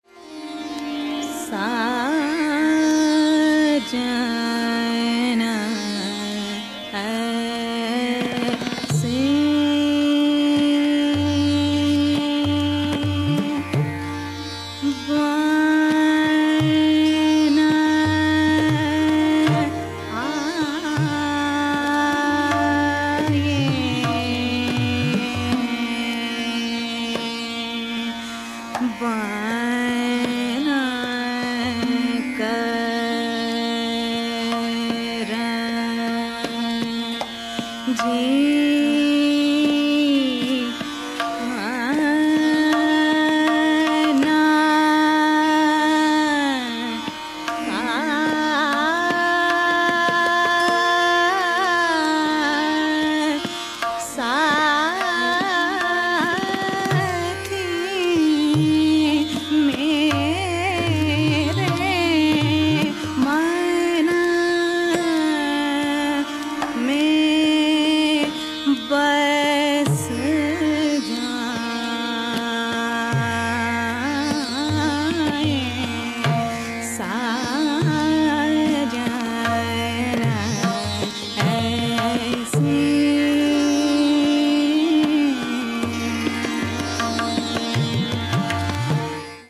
tabla
harmonium
Ektāla - Ati Vilambit
āvarta 1: introduction to sthā'ī